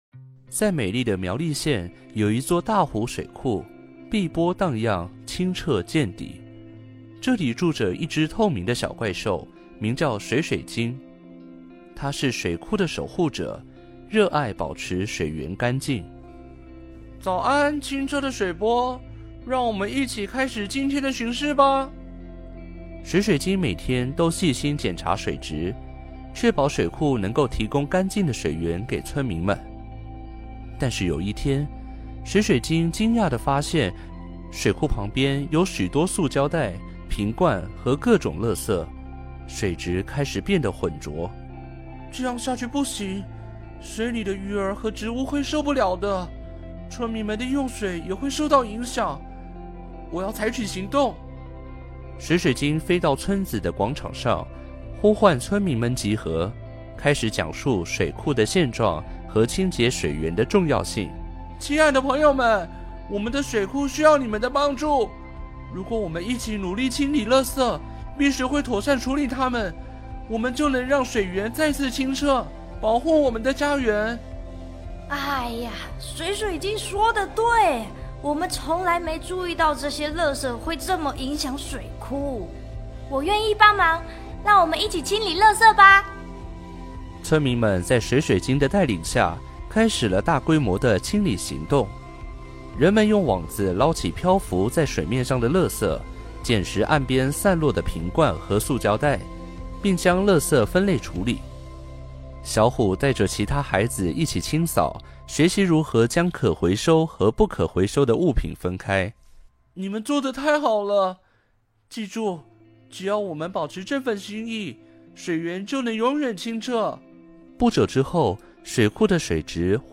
故事有聲書